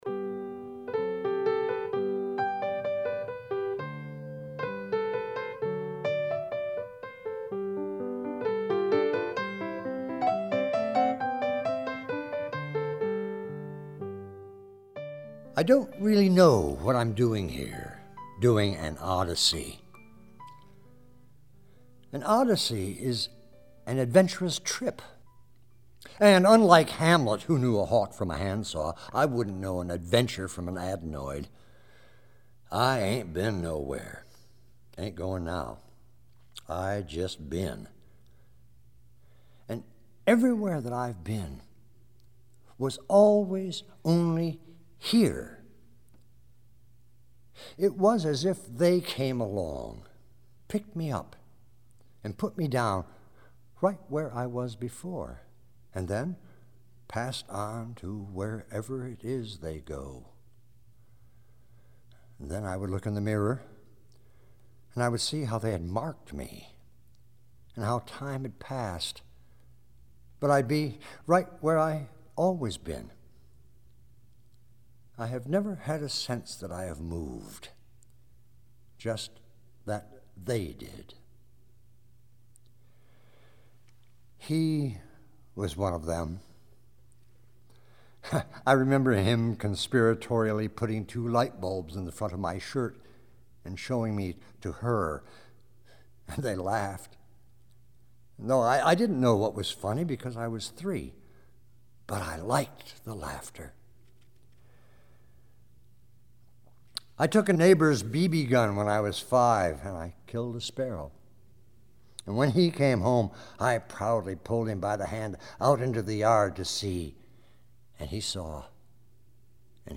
sermons
reading these sermons